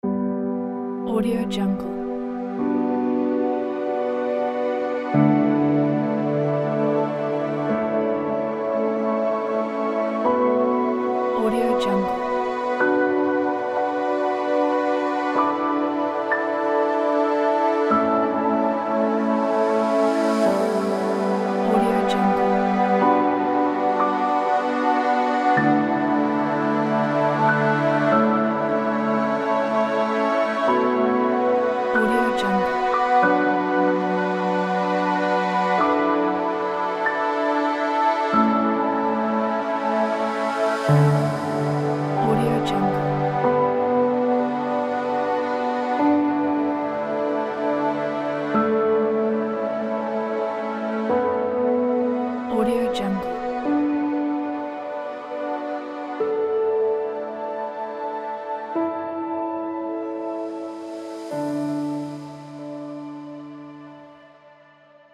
آمبیانس و آرام